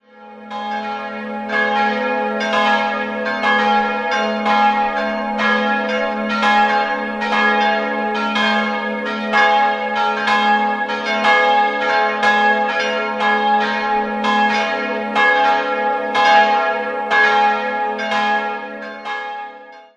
Jahrhundert wurde das Gotteshaus barockisiert. 3-stimmiges Gloria-Geläute: as'-b'-des'' Die große Glocke wurde 1950 von Karl Hamm in Regensburg gegossen, die beiden kleinen stammen von Johann Erhard Kissner (Stadtamhof) aus dem Jahr 1756.